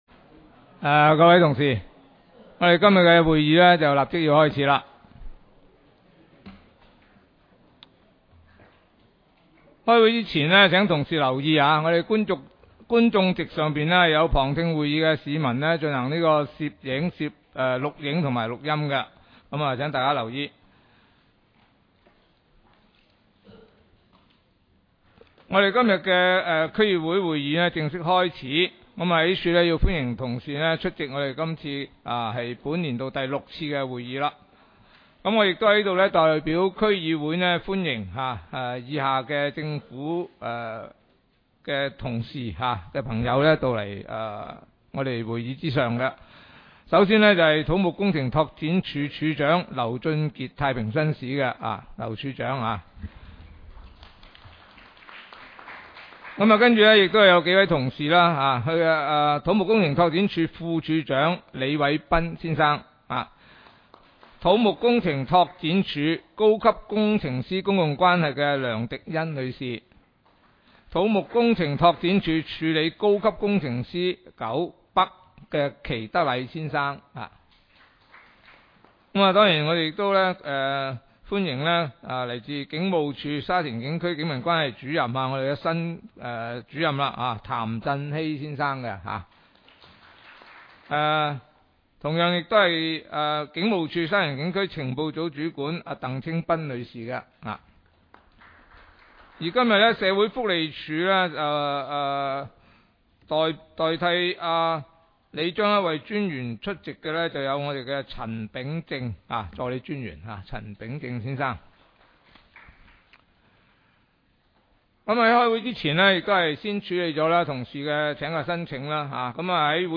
区议会大会的录音记录
地点: 沙田区议会会议室